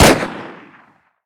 abakan_shot.ogg